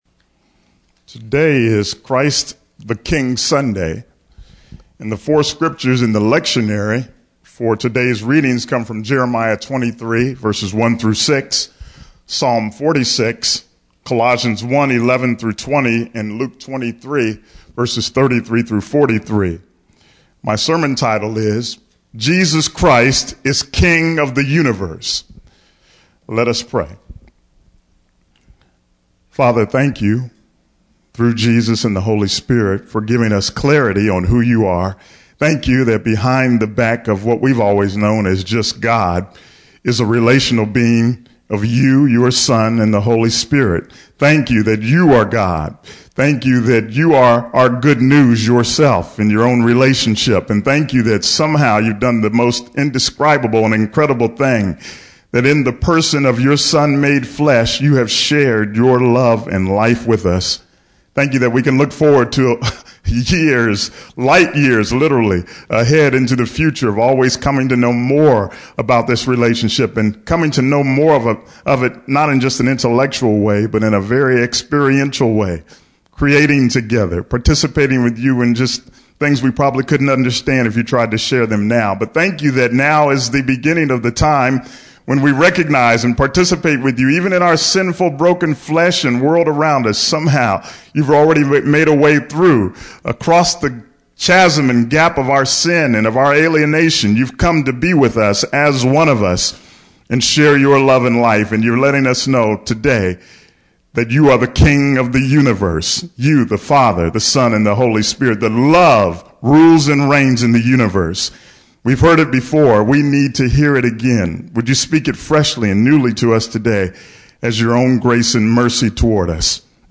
Proclaimed on Christ the King Sunday: This message proclaims the Personal, Relational and Present Truth of the Gospel that God the Father, Son and Spirit is His Gospel, and literally Reigns as King of the universe and all creation in the Person of Jesus! This means that creation is ruled By, In, Through, and For Love and yet not apart from the messiness and suffering we all experience!